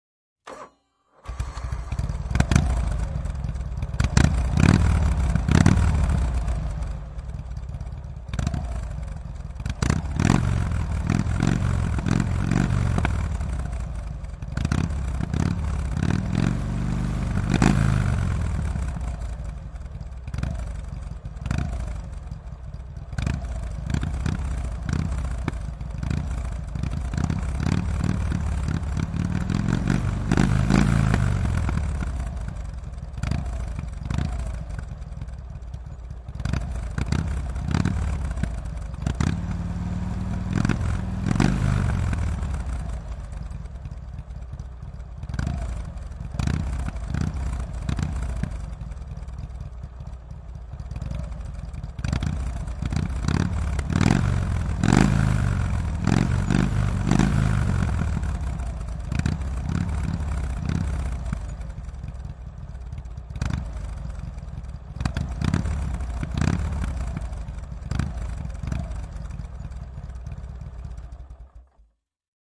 杂项 " Signaali, äänimerkki, dingdong / 信号，叮咚，在机场的公告或呼叫之前
描述：Kuulutustaddeltäväplingplong，dingdong signaali。 Sopiimyöspalvelutiskinvuoronmerkkiääneksi。 Äänitetty/ Rec：Analoginen nauha，Nagra / Analog tape，Nagra Paikka /地方：Suomi /芬兰/ Helsingin lentoasema（Seutula）/赫尔辛基机场Aika /日期：04.03.1971
声道立体声